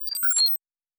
pgs/Assets/Audio/Sci-Fi Sounds/Electric/Data Calculating 1_4.wav at master
Data Calculating 1_4.wav